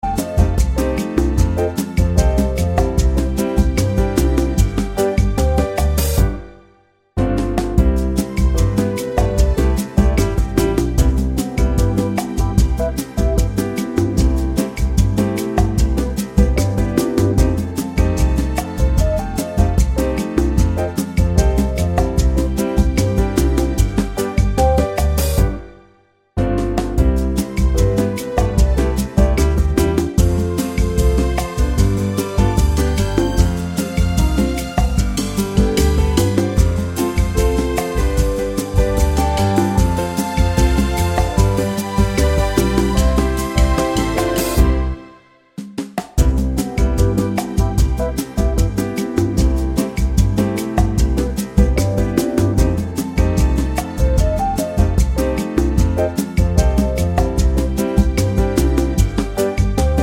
Original Key